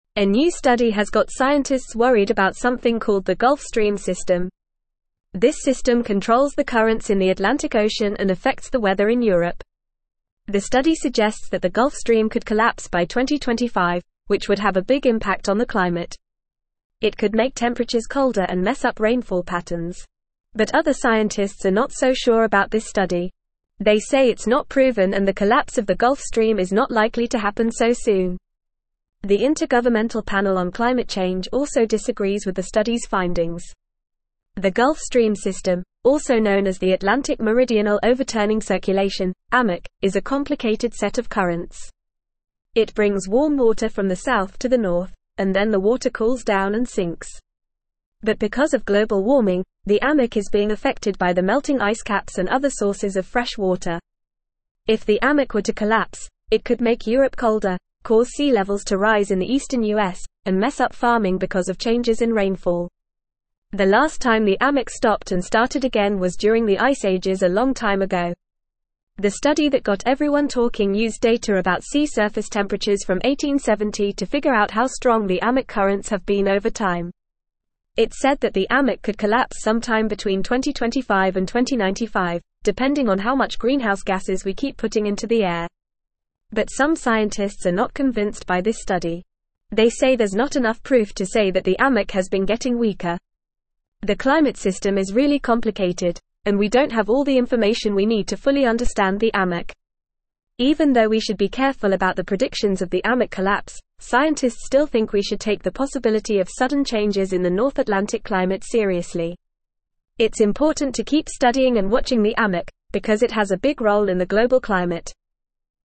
Fast
English-Newsroom-Upper-Intermediate-FAST-Reading-Gulf-Stream-System-Could-Collapse-Scientists-Express-Reservations.mp3